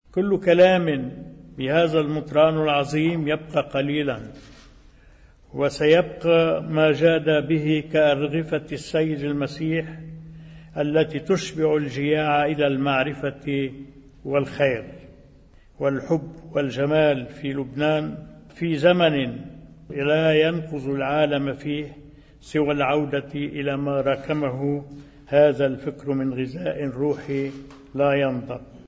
كلمة المطران خضر للرئيس عون في حفل تقليده وسام الأرز الوطني: